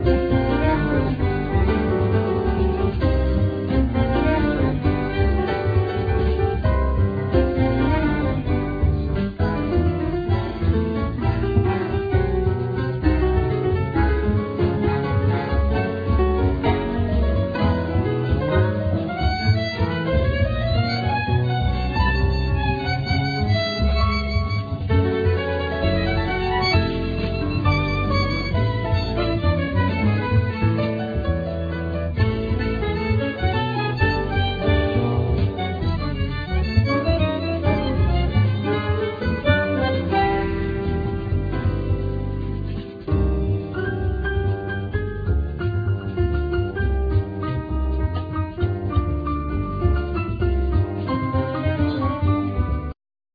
Piano
Bass
Drums
Bandneon
Violin
Sax